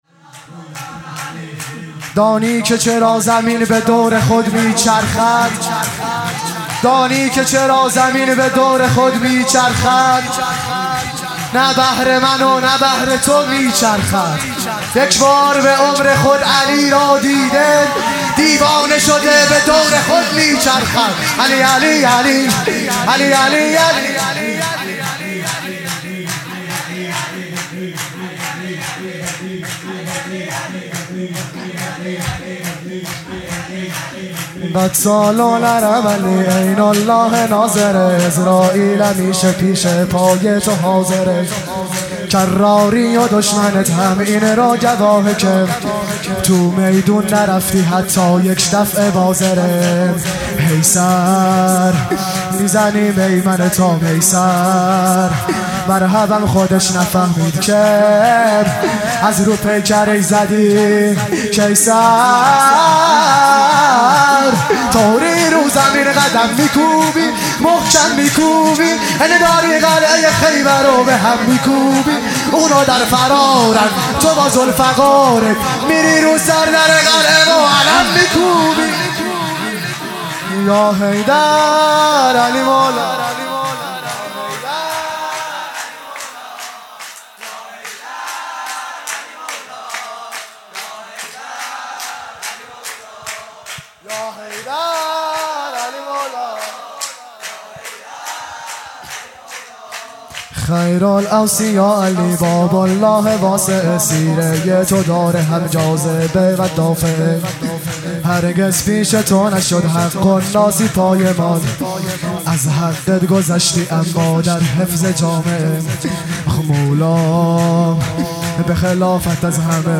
2 0 سرودسوم | قتال العرب علی